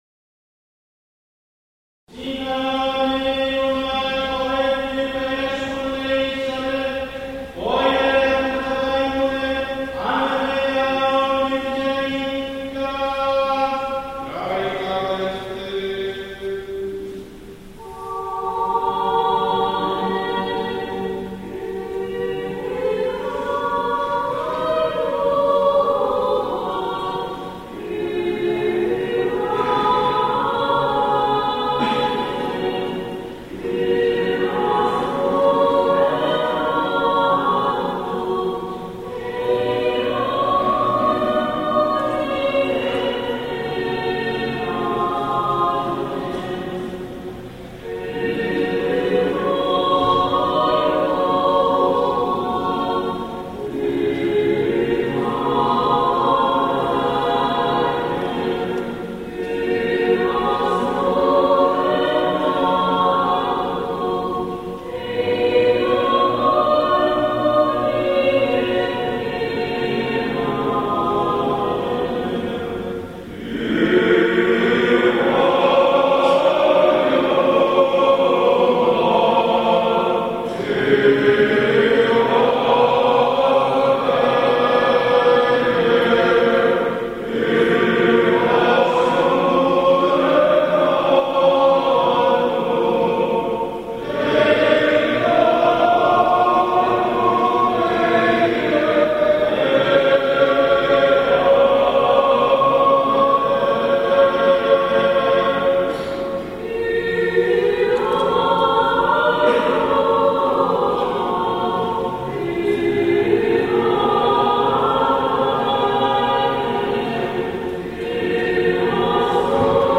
Liturgical music from Estonia
Metropolitan Stephanos, OCE clergy and combined choir :